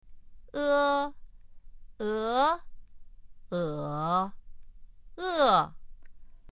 つぎに、それぞれの母音に声調（四声）のついた発音を確認してみましょう。
e1-4.mp3